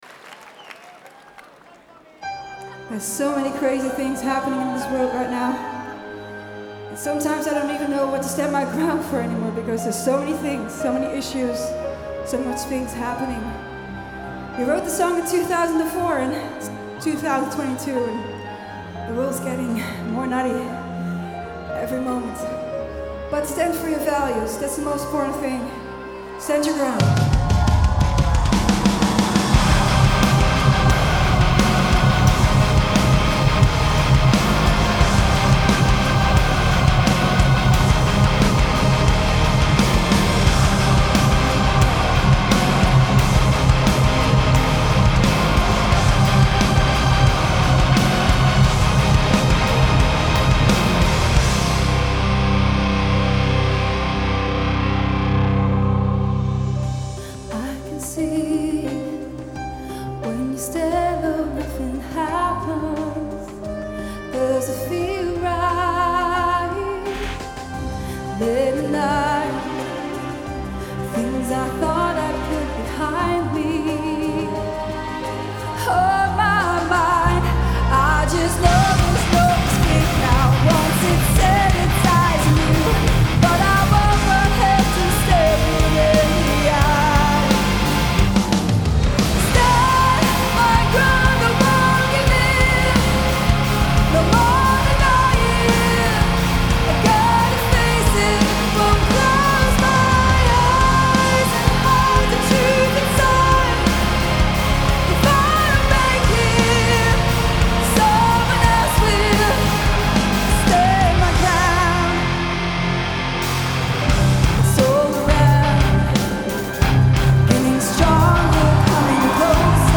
Genre : Hard Rock